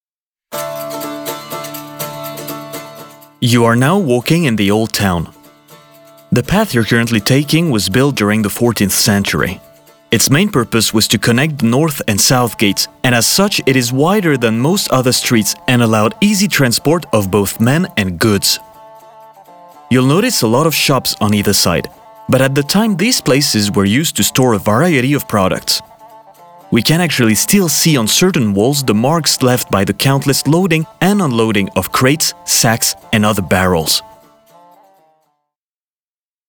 Naturelle, Polyvalente, Fiable, Amicale, Corporative
Guide audio
His natural mid-low voice sounds professional and trustworthy but also warm and friendly - perfect for corporate and educational content, while his versatility allows him to voice a wide range of characters and commercials.